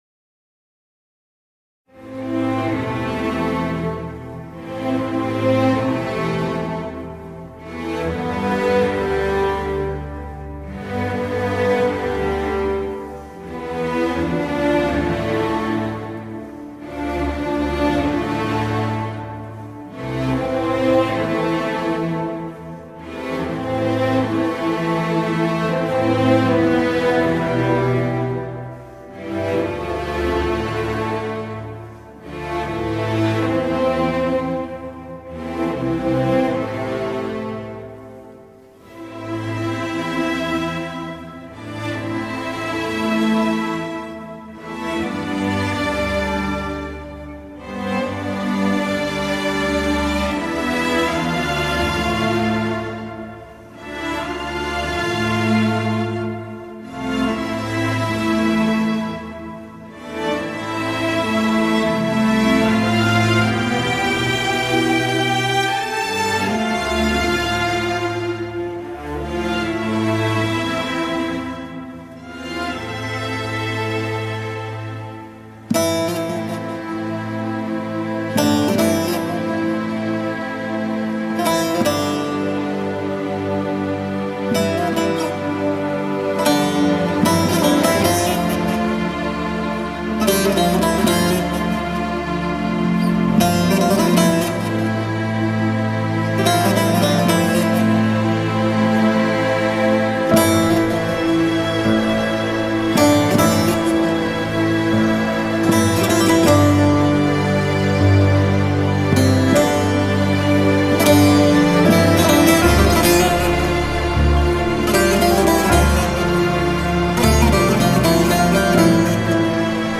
tema dizi müziği, duygusal hüzünlü üzgün fon müzik.